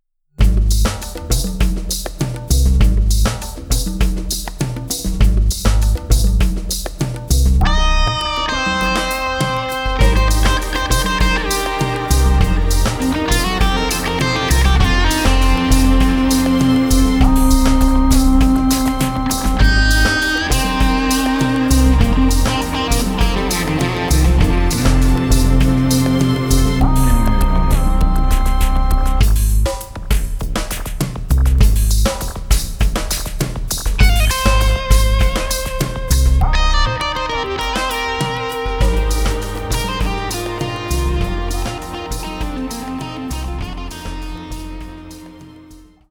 instrumental track